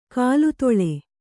♪ kālu toḷe